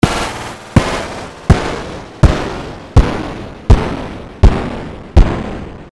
炸弹滴答倒计时
标签： 炸弹 倒计时 C4
声道单声道